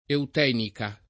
eutenica [ eut $ nika ]